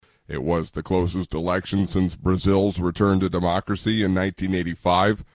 ディクテーション VOA News 22年10月31日午後７時（現地時間）のニュース ロシア軍によるウクライナのインフラ設備の攻撃が激化しているというニュースについてです。